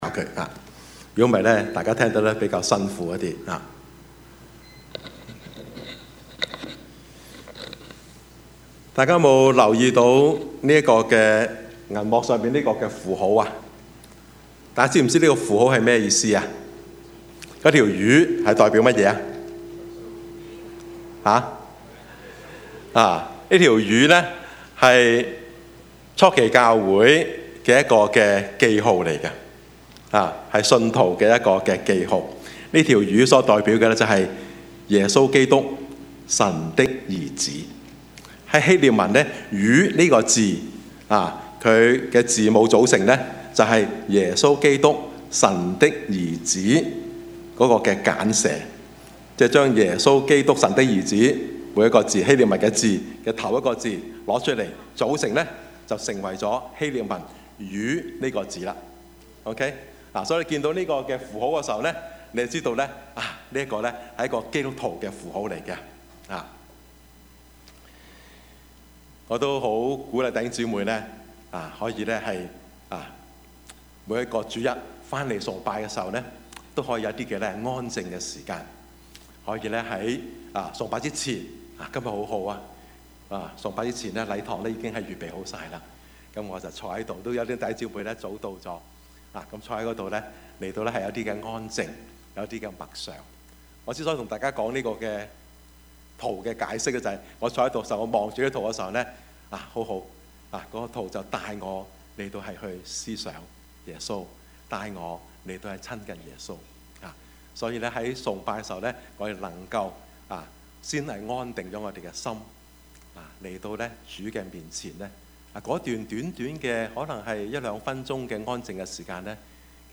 Service Type: 主日崇拜
Topics: 主日證道 « 叫人驚訝的耶穌 識時務者為俊傑 »